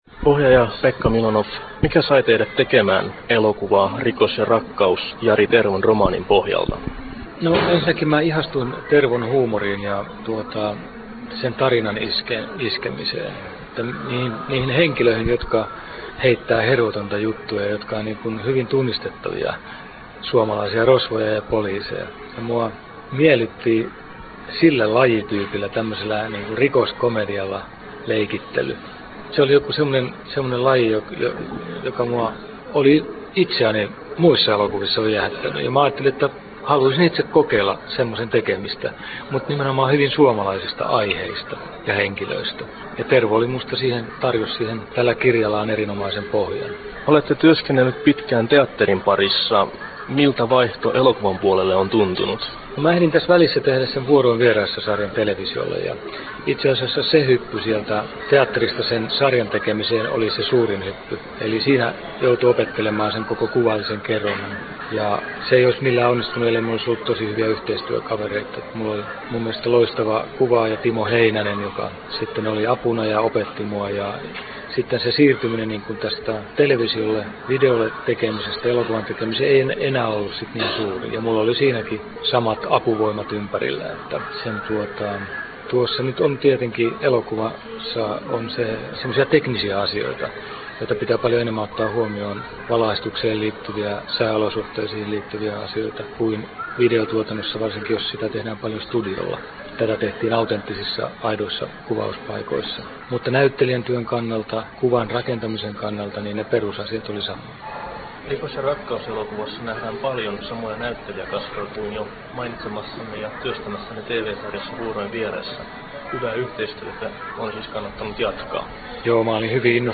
Nauhoitettu Turussa